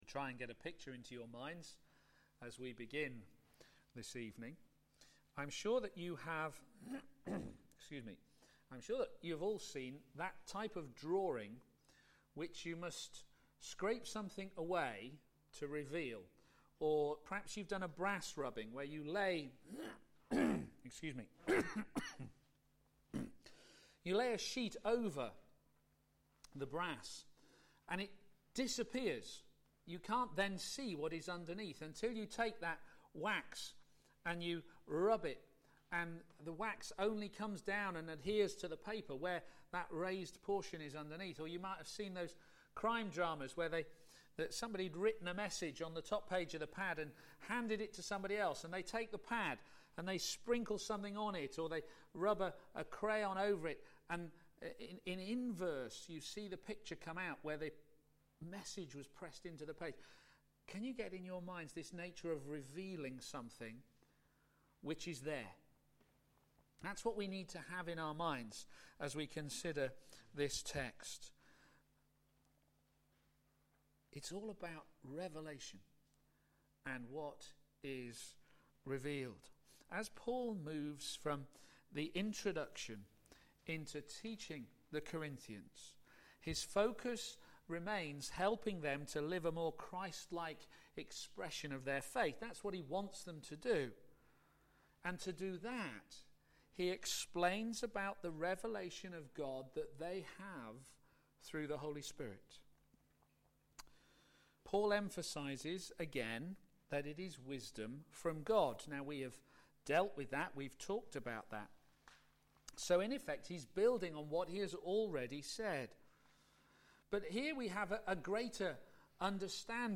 Media for p.m. Service on Sun 30th Jun 2013 18:30
Series: Working together to advance the Gospel Theme: God's Revelation Through the Holy Spirit Sermon